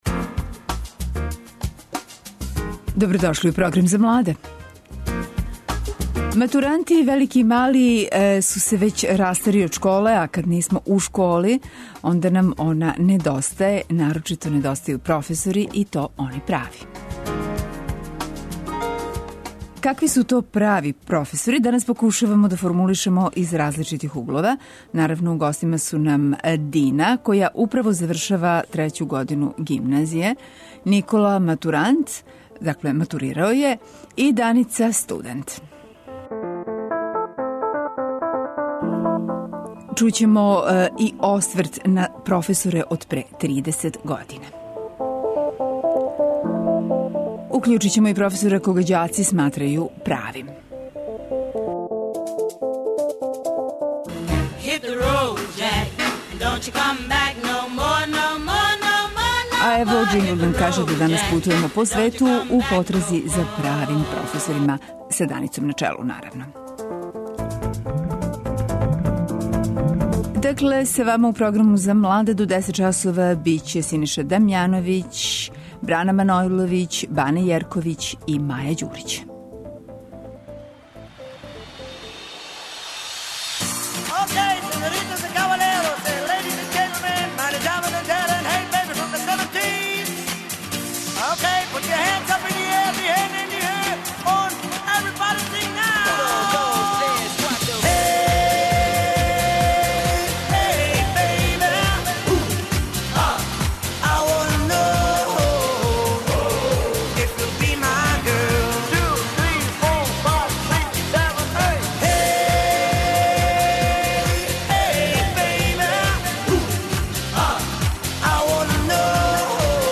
О томе говоре гости наше емисије - професори и београдски матуранти.